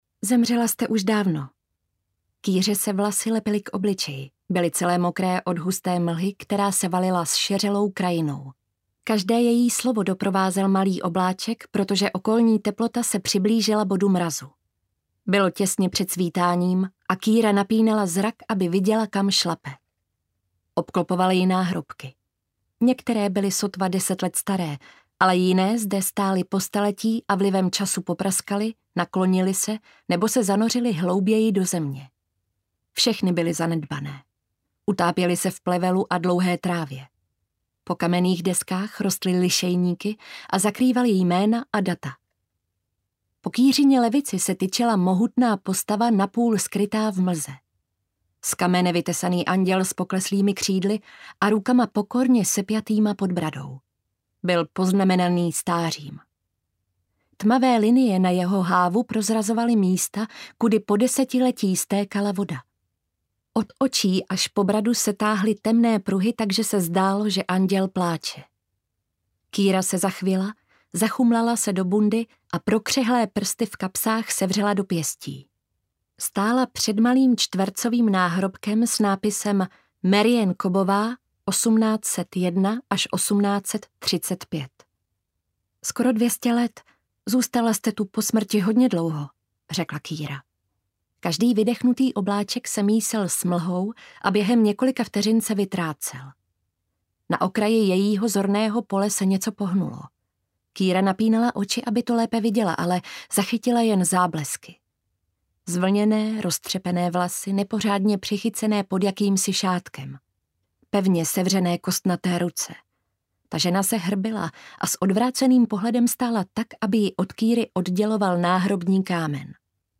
Nenasytní mrtví audiokniha
Ukázka z knihy